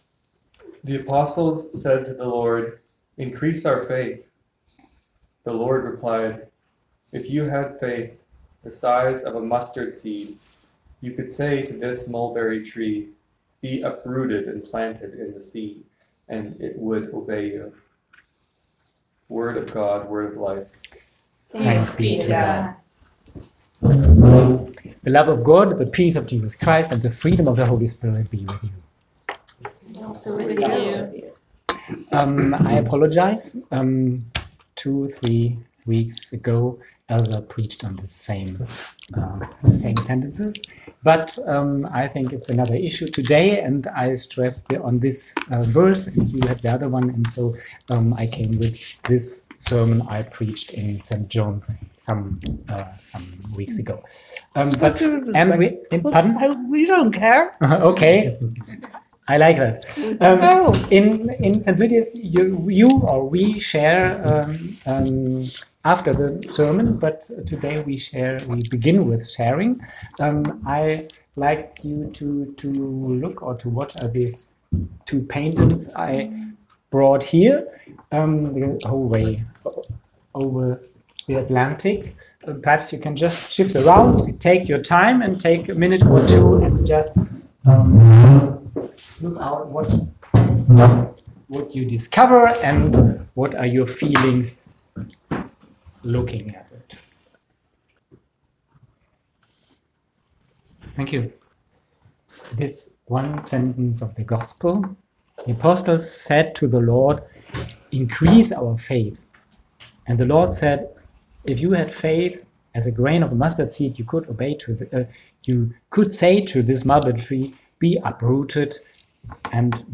St. Lydia's is a Dinner Church in Brooklyn, New York.
October 20, 2019 Sermon